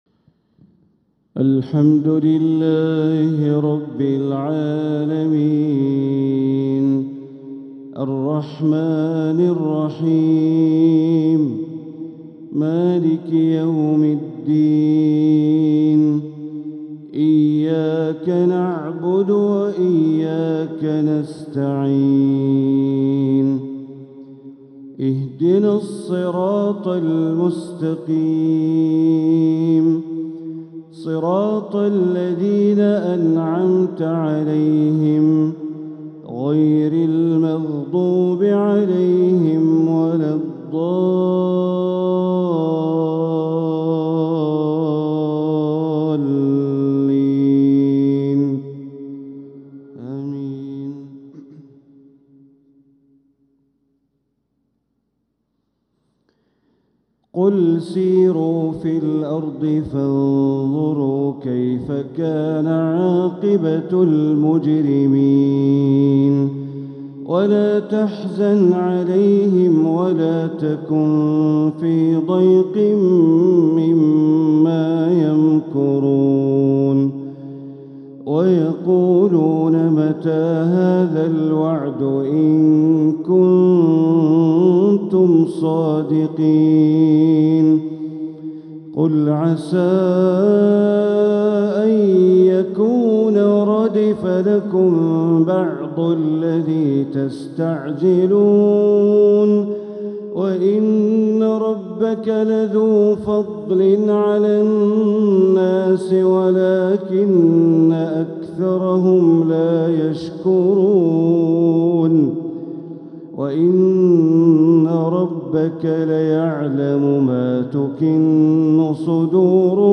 تلاوة لخواتيم سورة النمل | فجر الخميس ٢٢ محرم ١٤٤٧ > 1447هـ > الفروض - تلاوات بندر بليلة